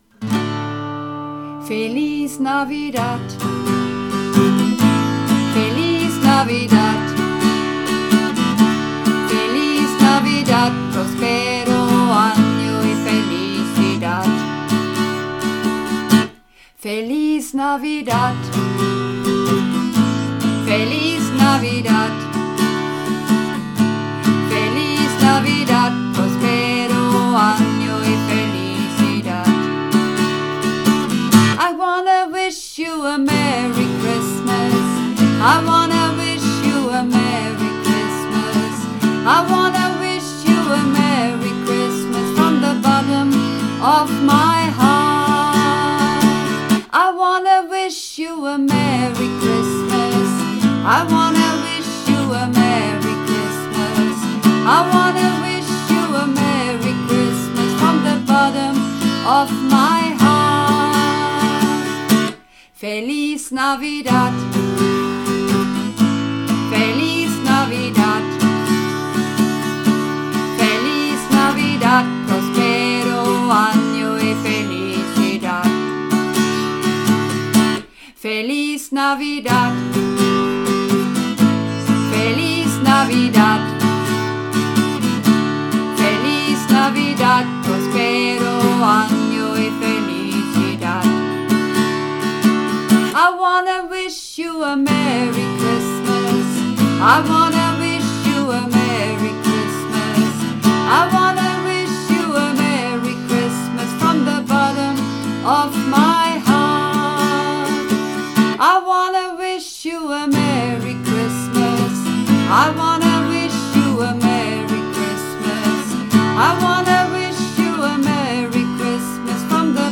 Übungsaufnahmen - Feliz Navidad
Feliz Navidad (Alt)
Feliz_Navidad__1_Alt.mp3